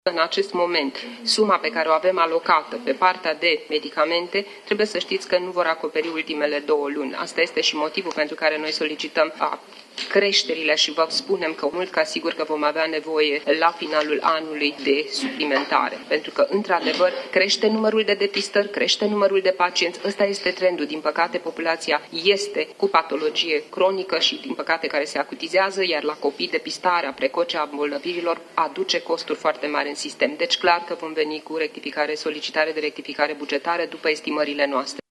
Între timp, tot la dezbateri, Casa nationala de Asigurări de Sănătate spune că bugetul aprobat pentru 2025 ajunge doar pentru 10 luni de medicamente compensate